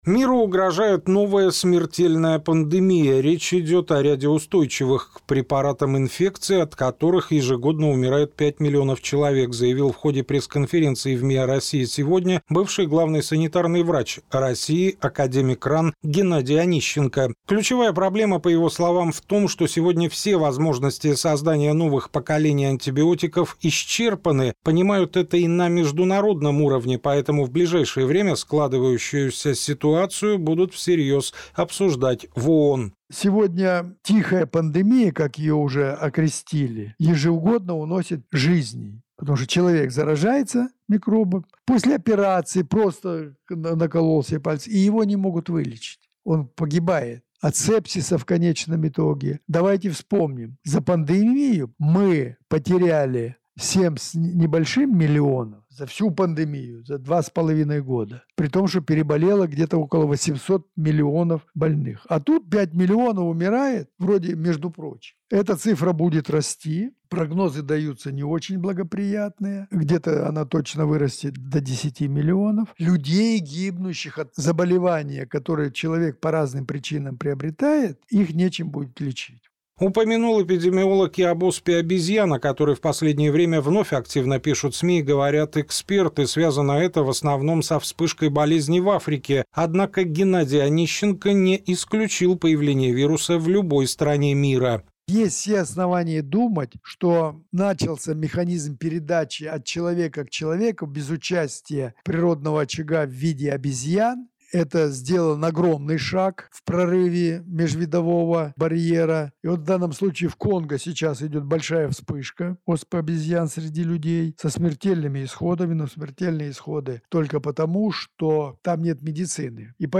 Как заявил в эфире НСН экс-глава Роспотребнадзора Геннадий Онищенко, если в 2021 году воздушно-капельной инфекцией болели чуть более тысячи человек, то сейчас уже почти 4,5 тысячи.